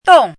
chinese-voice - 汉字语音库
dong4.mp3